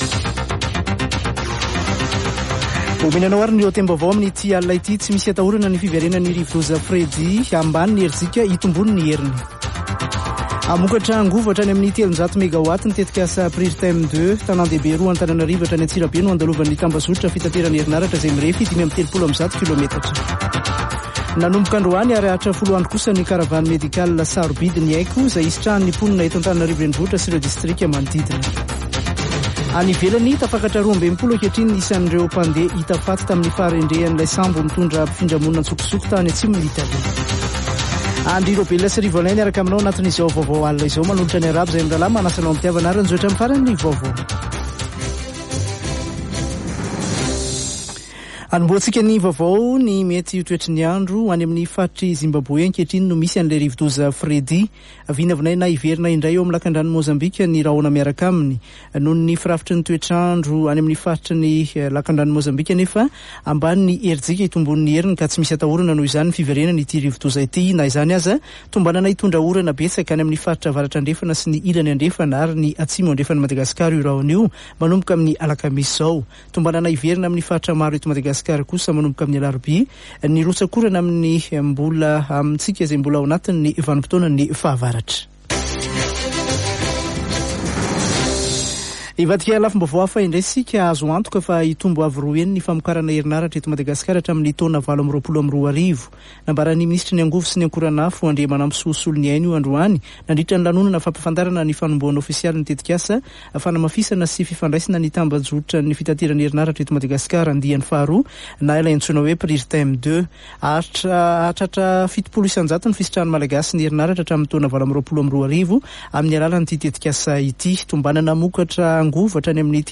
[Vaovao hariva] Alatsinainy 27 febroary 2023